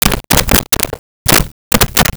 Switchboard Telephone Dial 03
Switchboard Telephone Dial 03.wav